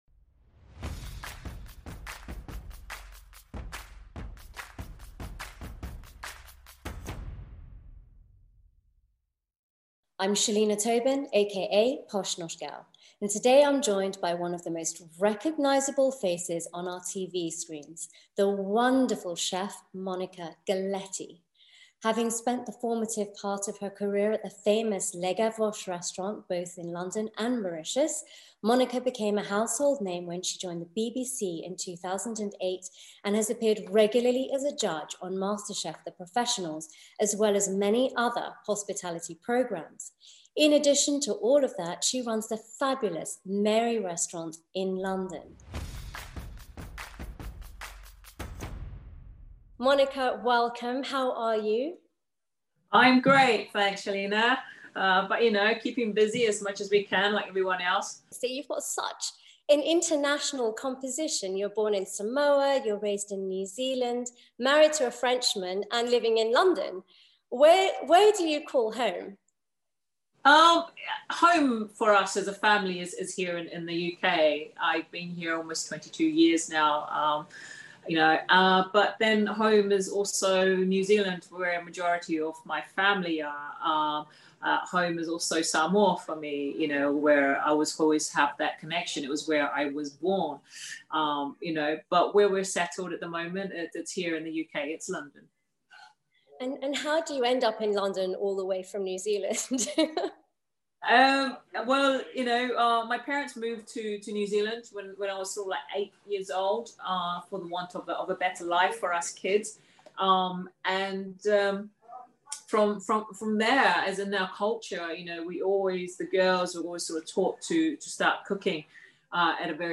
Monica chats with me about her journey as a chef and inspirations within the industry. We talk about her stunning restaurant Mare and how she manages a work life balance.